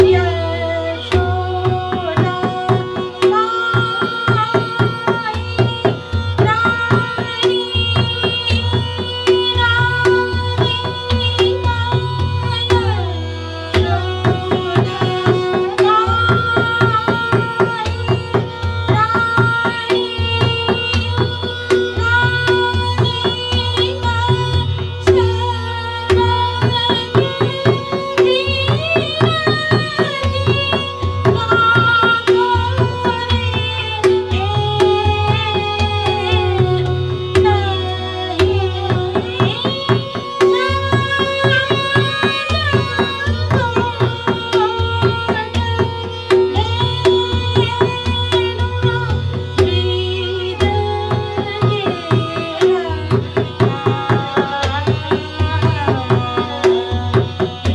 The antara spans across the middle notes of the middle octave (madhya saptak) and extends towards the higher octave (taar saptak).